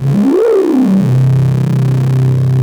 OSCAR 10 C1.wav